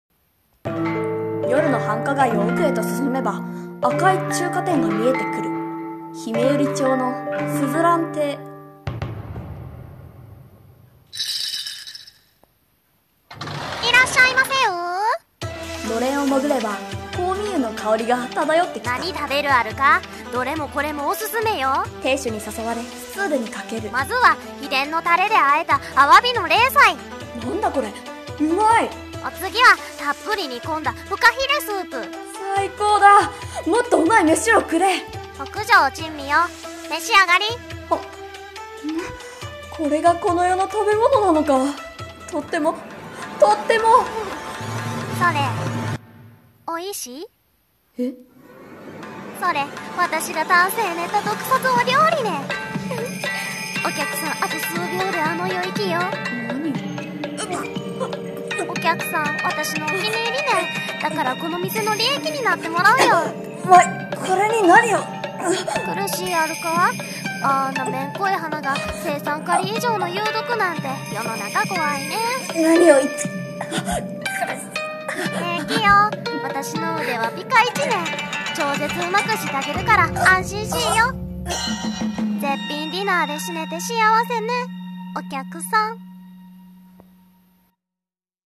声劇】珍味の鈴蘭中華店